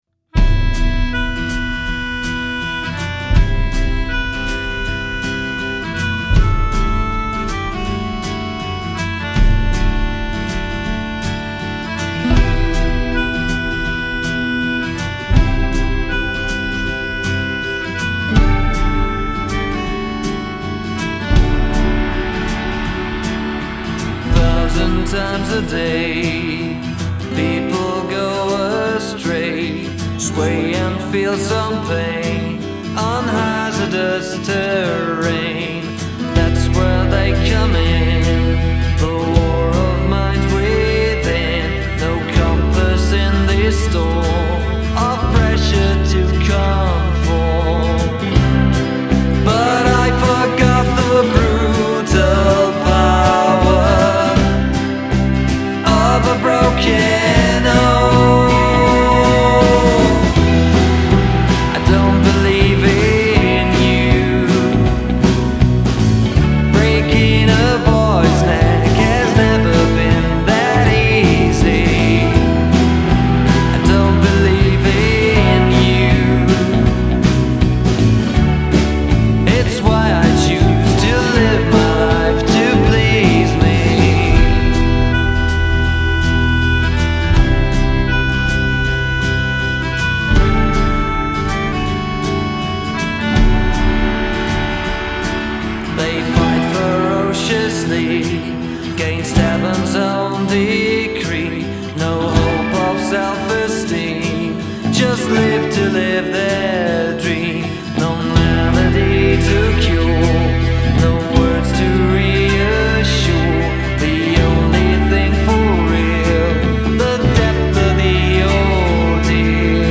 Ist das Indie, ist das Pop?